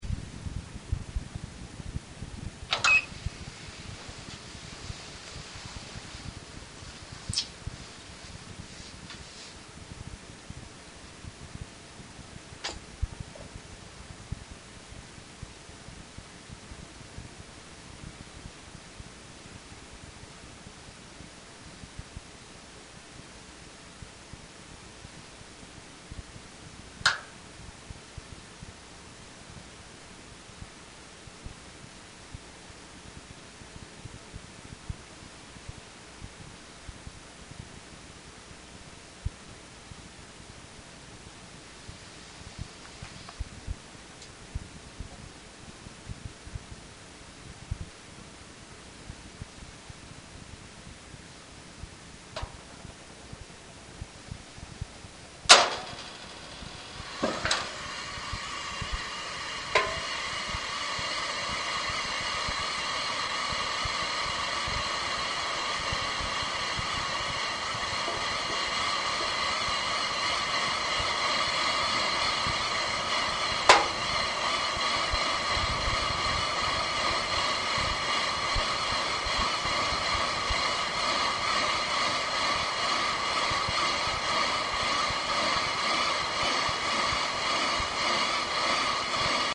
コールドスタート時の音　8℃　1.5分間
dainichi-coldstart-8deg.mp3